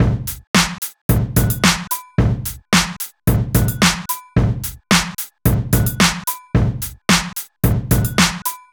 30 Drumloop.wav